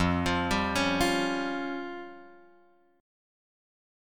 Fsus2#5 chord {1 x 3 0 2 1} chord